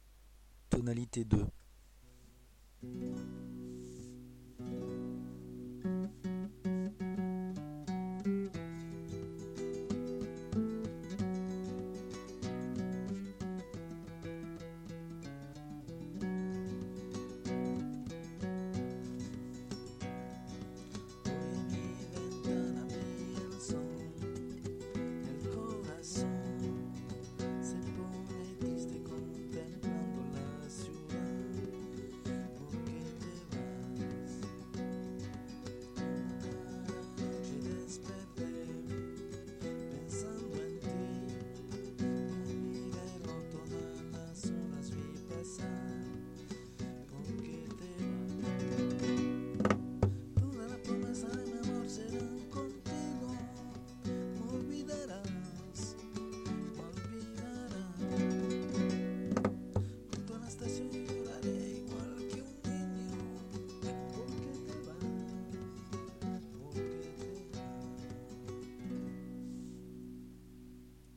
os presentan este Dueto